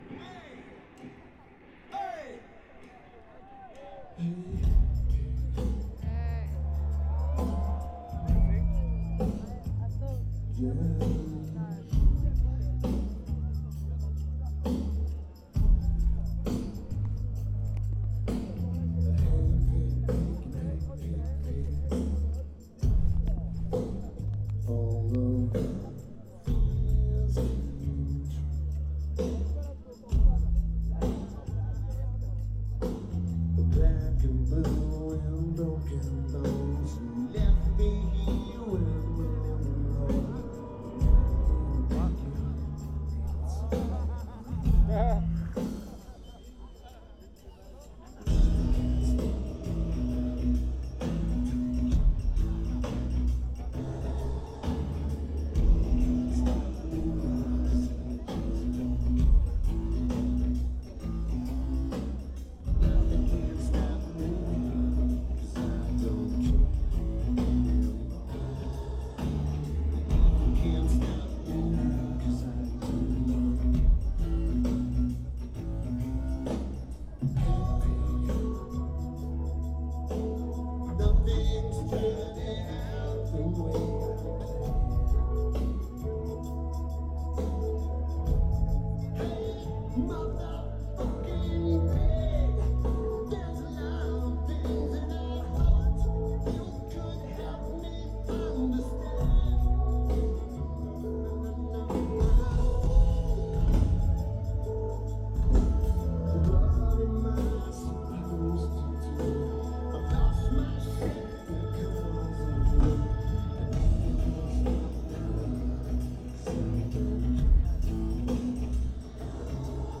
Hellfest
Lineage: Video - AUD (Zoom H4N)